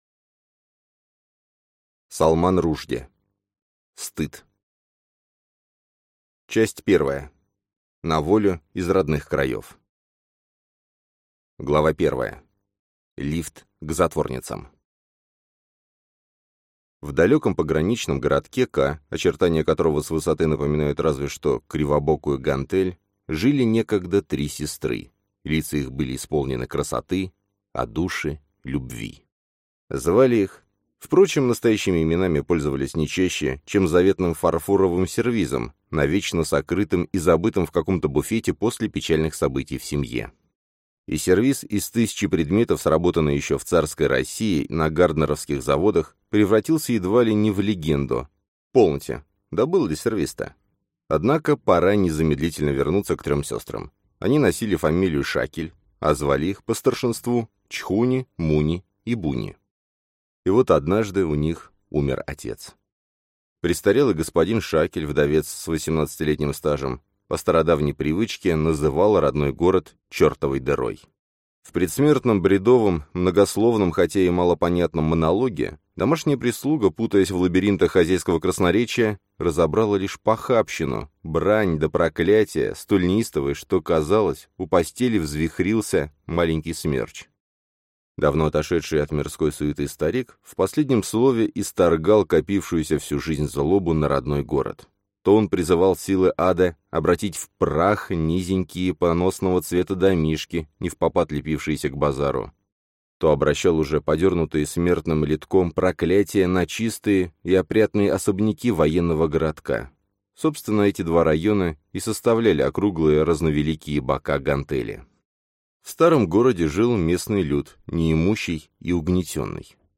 Аудиокнига Стыд | Библиотека аудиокниг